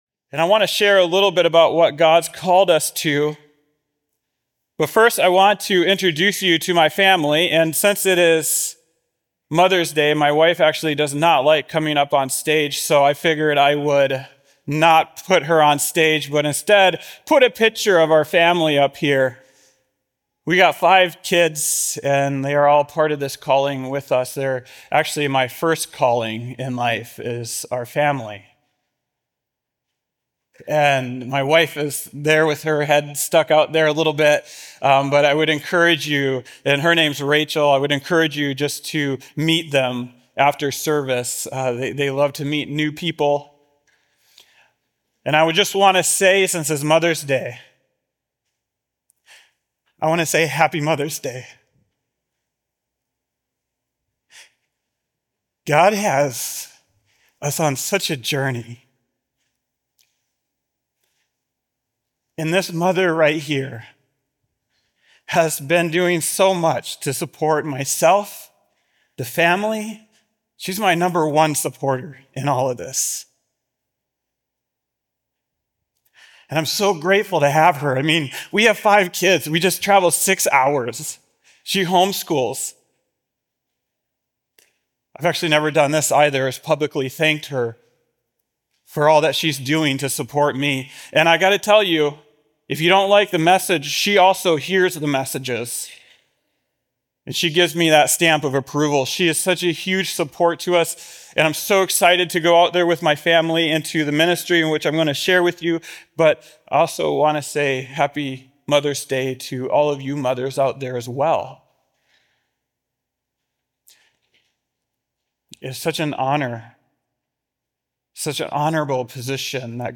Christmas Eve sermon